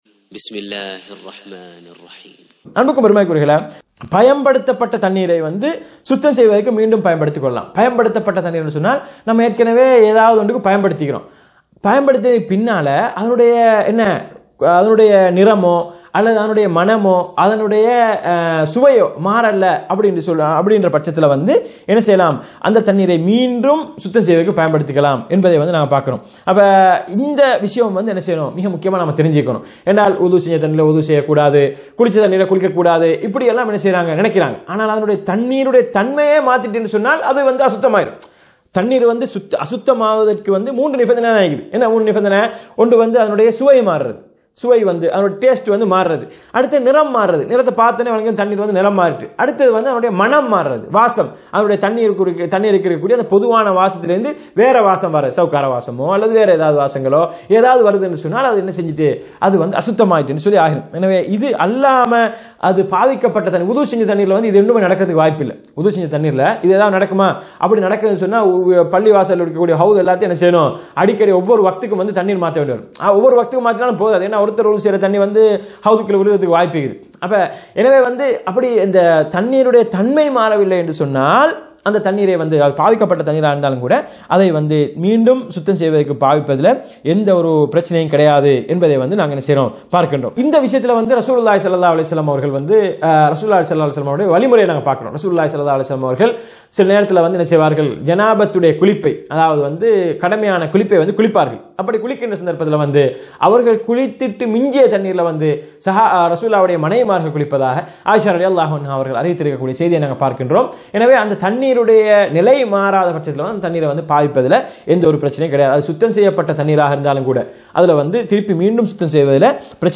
சுத்தம், அசுத்தம் பற்றிய பாடங்களின் விளக்கங்களிலிருந்து…